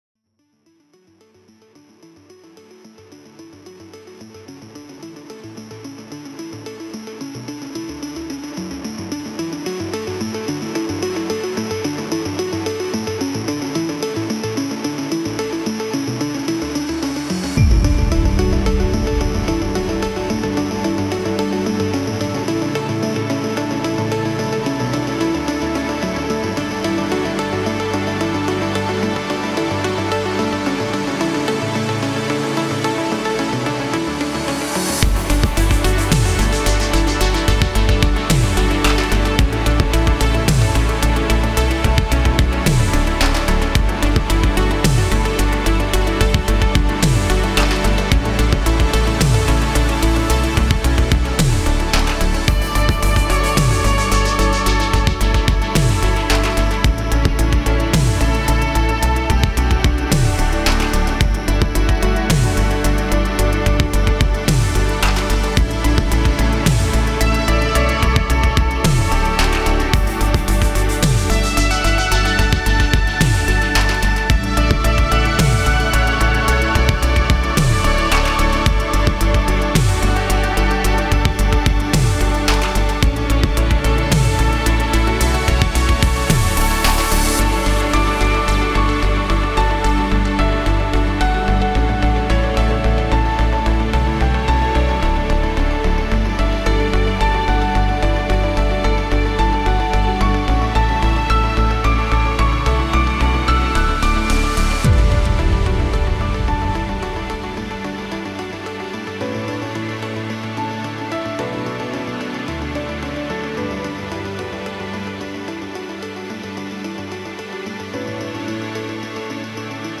Wanted to make something chill, like, conjuring feelings of being outside at night, winter time, snow everywhere, just laying on your back in the snow, watching the Aurora dancing in the skies above.